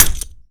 weapon
Grenade Bounce 6